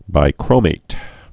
(bī-krōmāt, -mĭt)